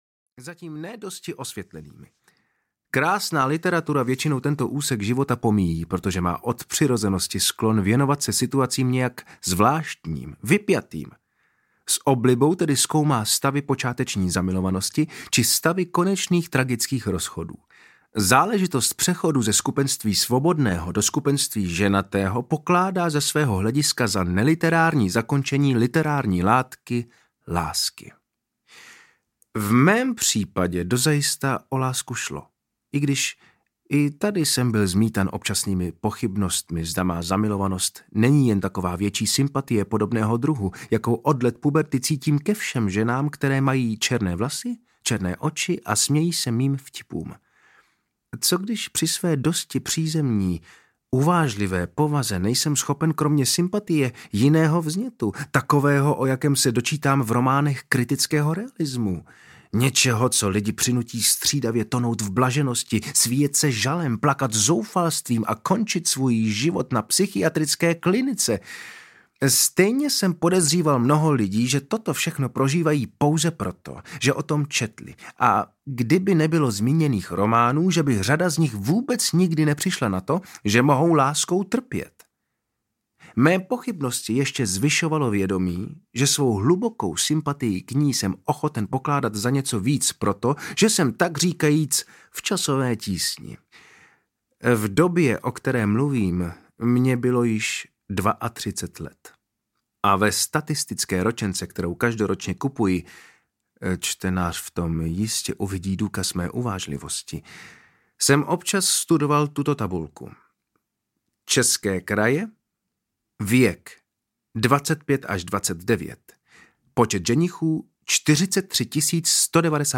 Svatební cesta do Jiljí audiokniha
Ukázka z knihy
Oba se nesmírně osobitě zmocnili textu. S chutí, humorem a jemnou ironií vyprávějí historii předsvatební cesty přírodovědce a jeho milované empatické dívky.
• InterpretTatiana Vilhelmová, Vojtěch Dyk